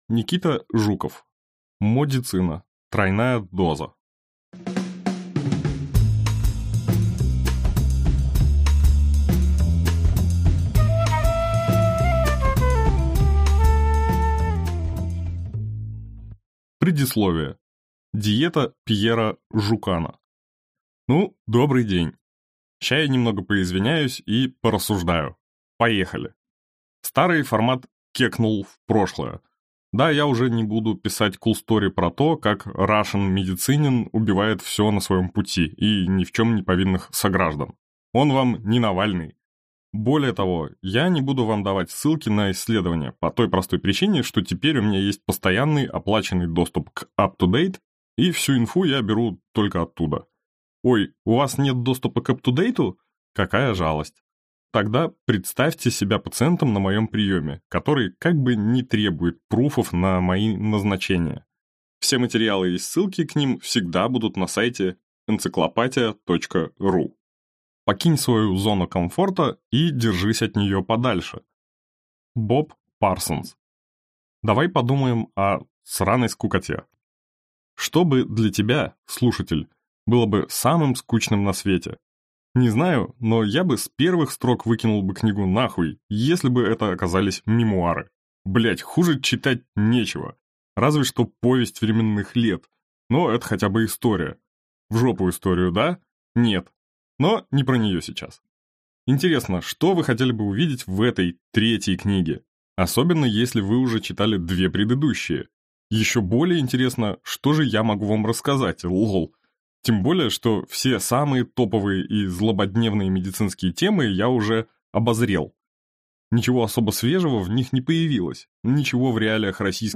Аудиокнига Модицина.